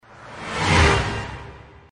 OvertakingSound_5.mp3